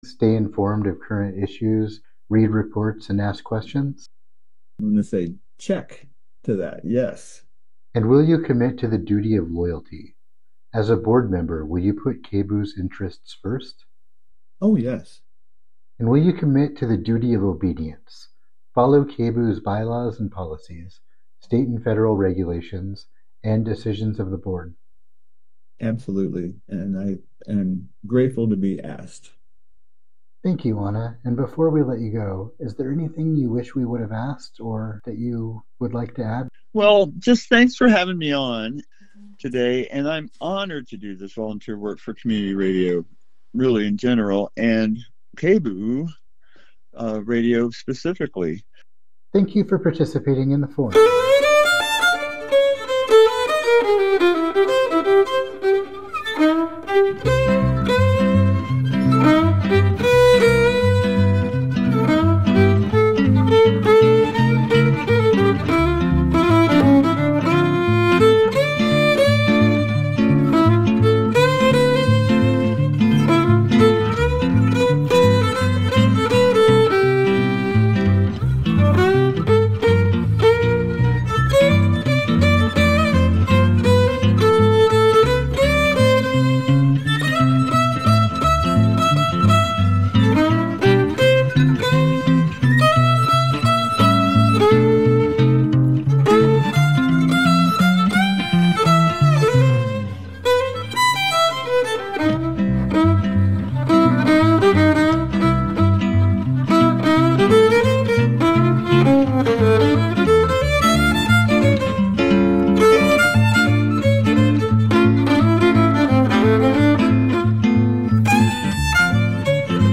In a sentence: A panel broadcast reviewing the latest films and shows from the multiplex to the arthouse and beyond.